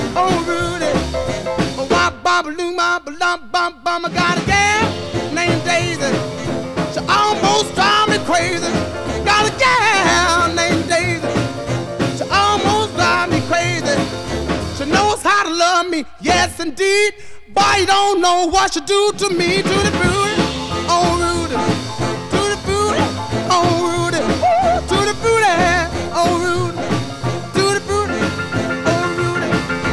Genre: R&B/Soul